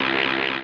flying.wav